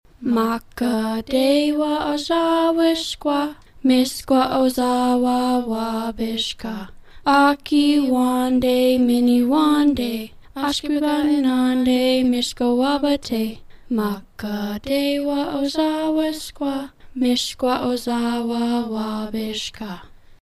From our administrative team to teachers who sing Ojibwe songs, to our classrooms actively participating, everyone is engaged in bringing this project to life.
the-color-song-ojibwe.mp3